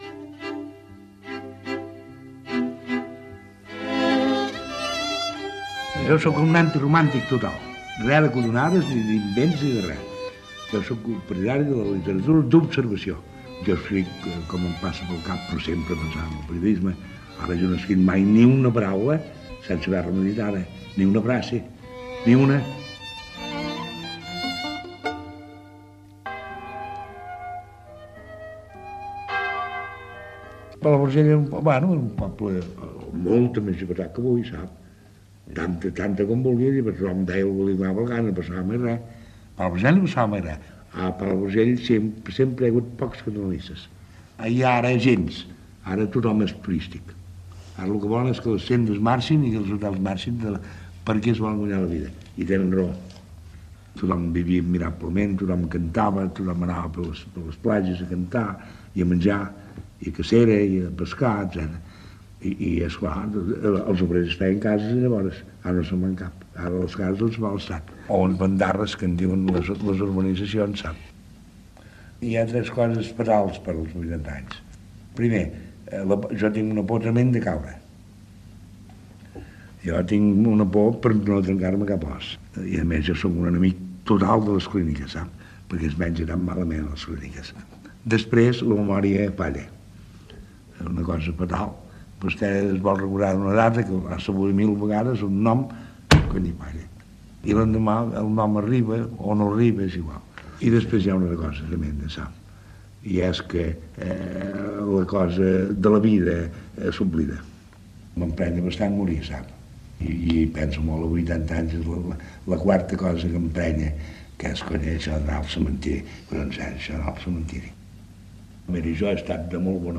Espai dedicat a l'escriptor Josep Pla amb declaracions seves sobre els seus escrits, l'edat, com havia viscut i la política